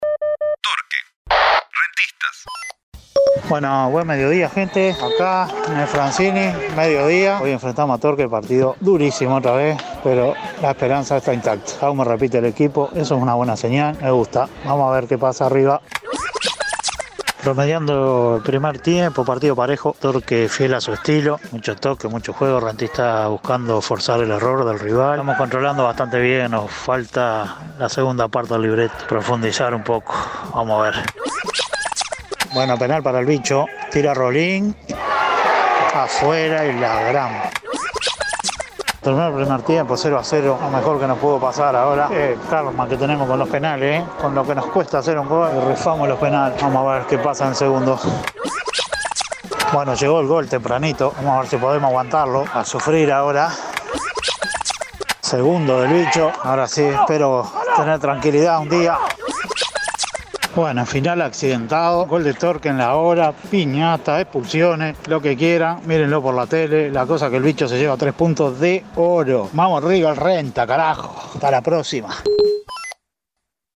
Los partidos de la sexta fecha del Torneo Clausura del fútbol uruguayo vistos y comentados desde las tribunas.